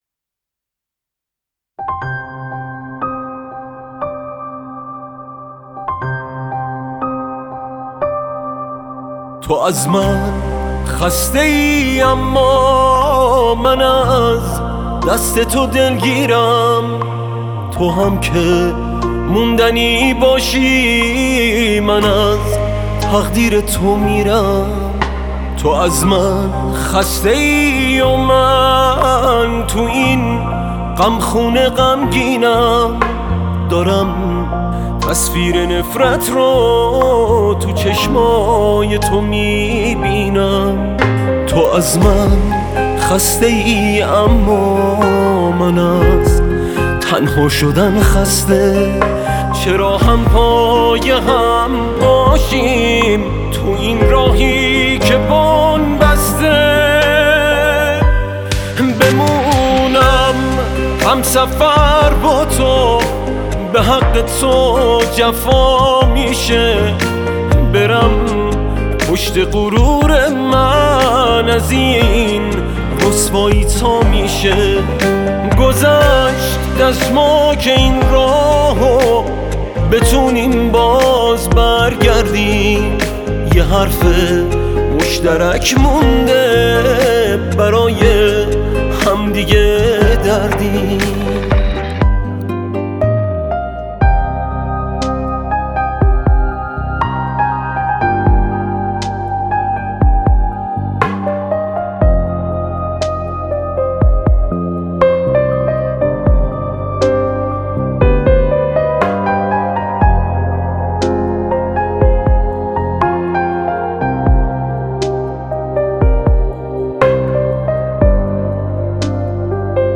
پاپ
اهنگ ایرانی